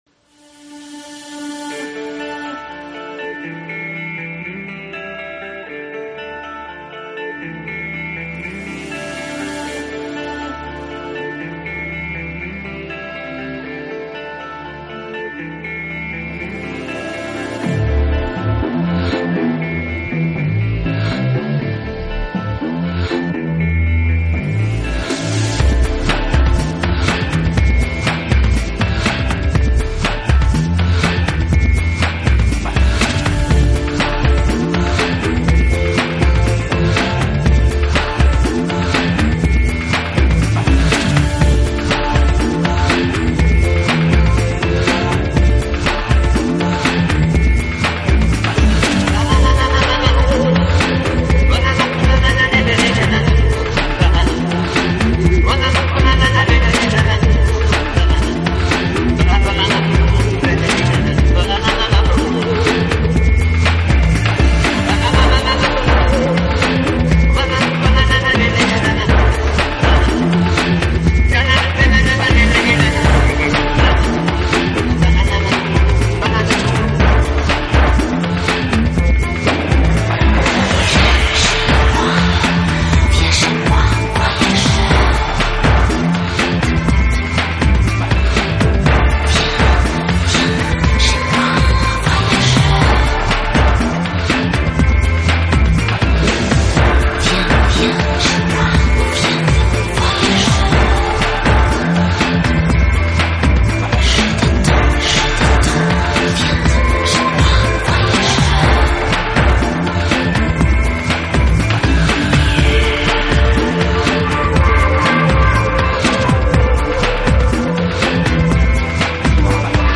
专辑类型：New Age